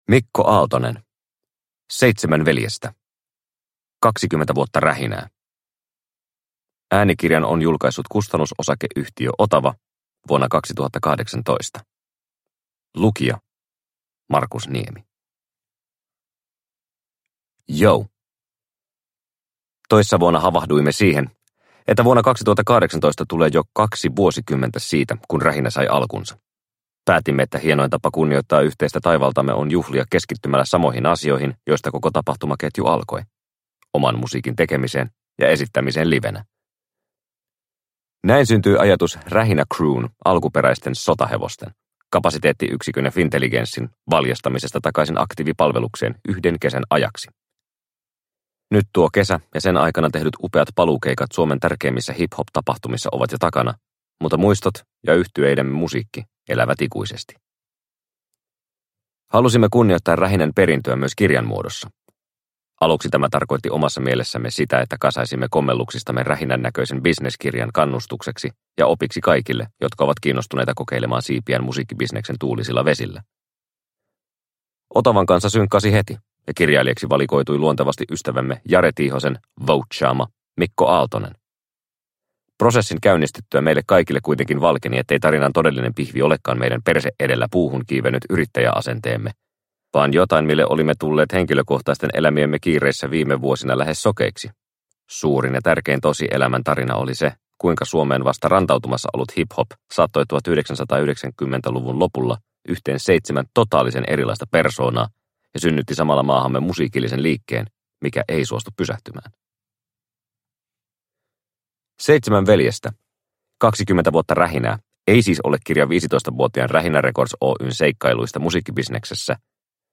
7 veljestä – Ljudbok – Laddas ner